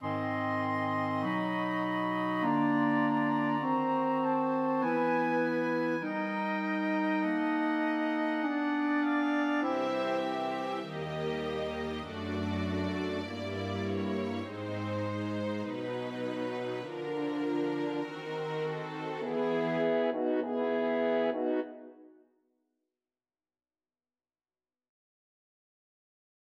예를 들어 바그너의 오페라 ''발퀴레'' 3막의 "잠자는 모티브"는 하강하는 색음계를 통해 다양한 관현악 음색을 보여준다. 목관악기(플루트, 오보에), 현악기, 금관악기(프랑스 호른) 순서로 연주된다.